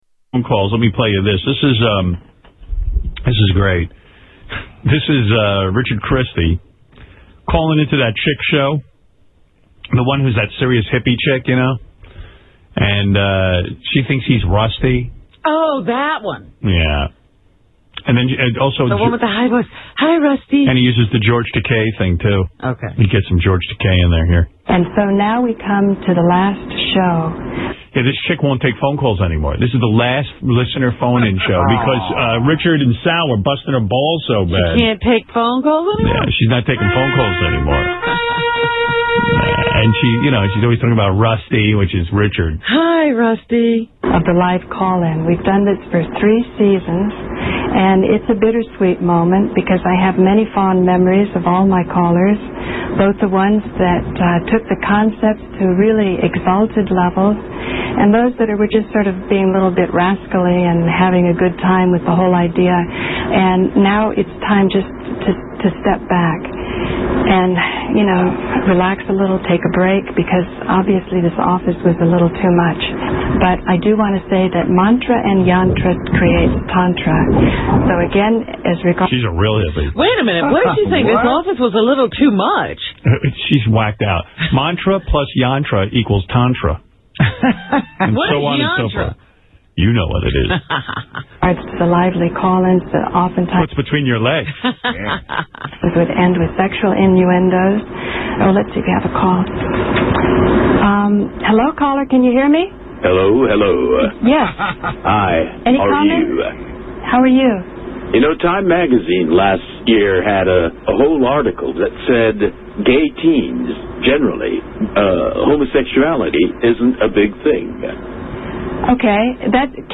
Tags: Media Howard Stern Dolly Parton Howard Stern Show Dolly Parton's Audio Book